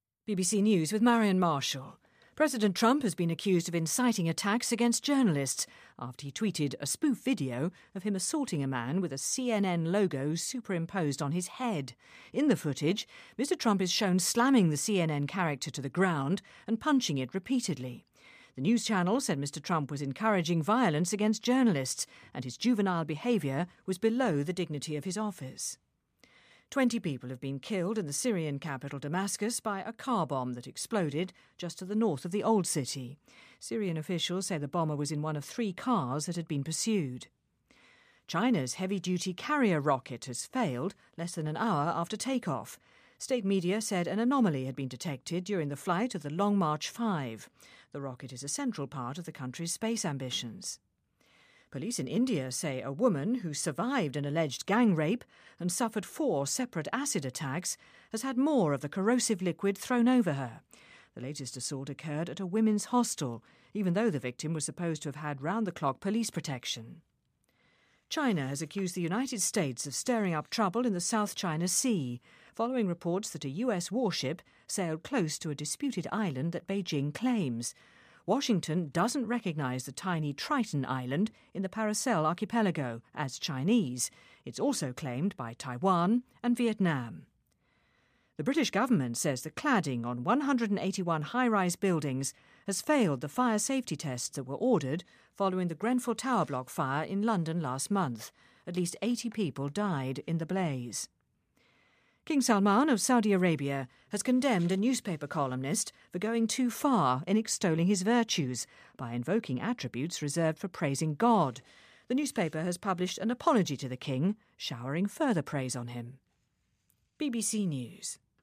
日期:2017-07-04来源:BBC新闻听力 编辑:给力英语BBC频道